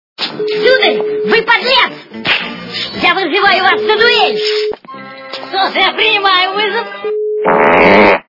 » Звуки » Смешные » Обкуренный голос - Сударь, я вызываю Вас на дуель...
При прослушивании Обкуренный голос - Сударь, я вызываю Вас на дуель... качество понижено и присутствуют гудки.